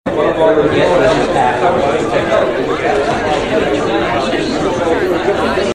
PEOPLE MURMURING IN HALL.mp3
Original creative-commons licensed sounds for DJ's and music producers, recorded with high quality studio microphones.
people_murmuring_in_hall_sac.ogg